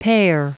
Prononciation du mot pare en anglais (fichier audio)
Prononciation du mot : pare